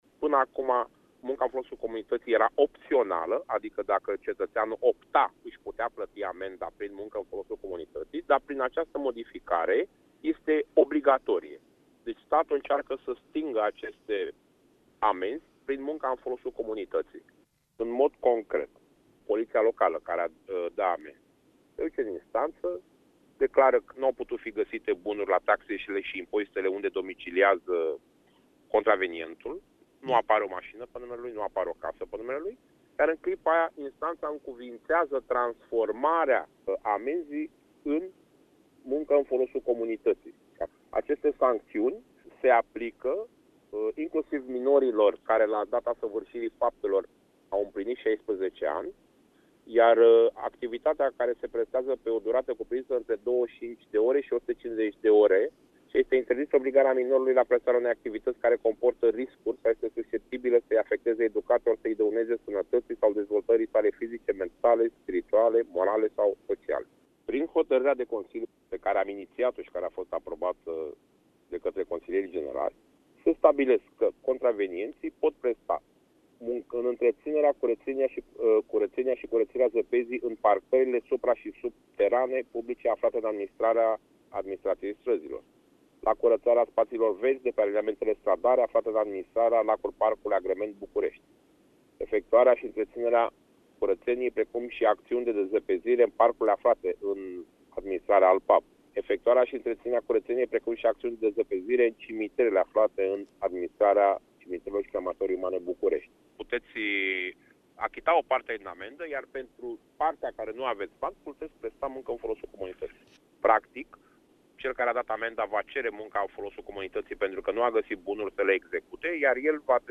Cristinel Diaconescu, consilier municipal,  inițiator al proiectului privind munca în folosul comunității: